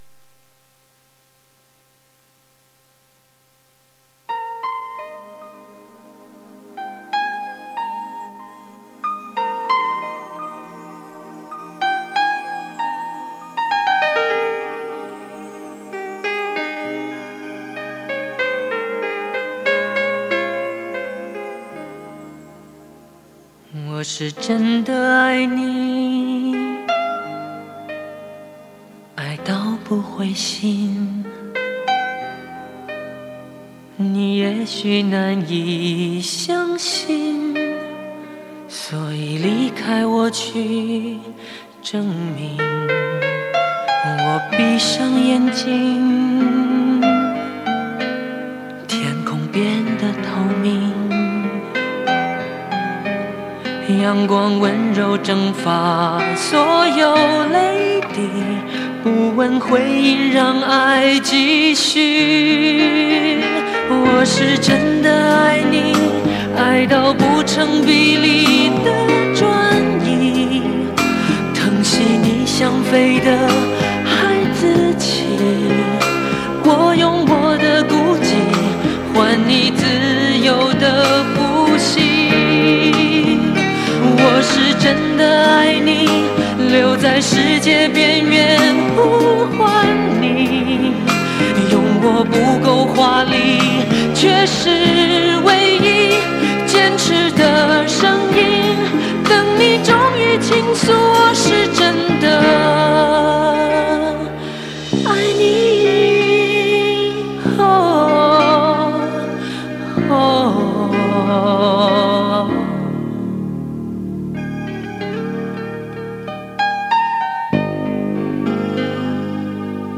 故事讲的是“异地恋”，不过这首歌从歌词到旋律都没有主题那么惊世骇俗，管弦乐大量的铺排与中板的温吞节奏，让这首充满了温情。